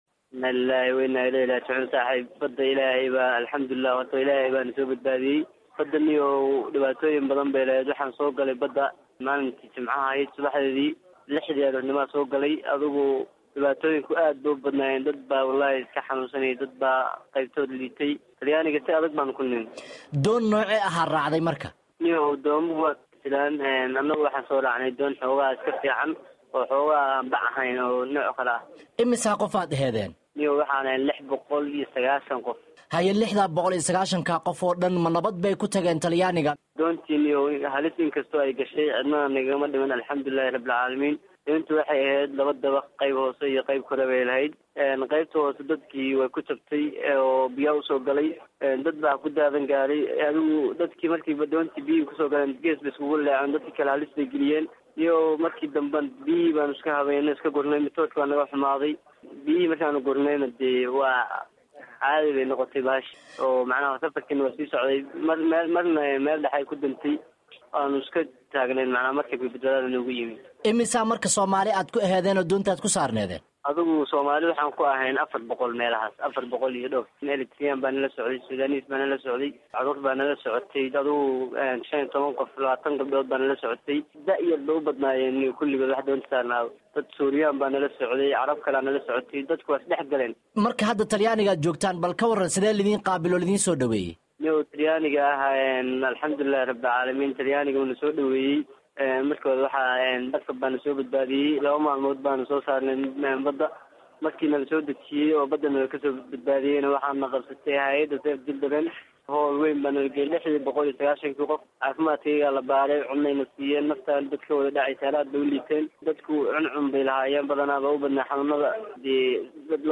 khadka telefoonka ugu warramay.